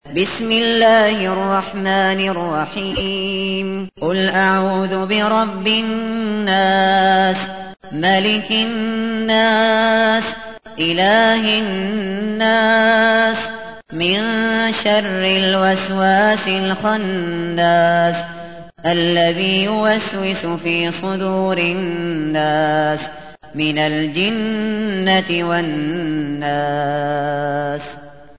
تحميل : 114. سورة الناس / القارئ الشاطري / القرآن الكريم / موقع يا حسين